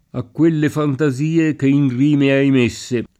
messo [m%SSo] part. pass. di mettere — es. con acc. scr.: A quelle fantasie che in rime hai mésse [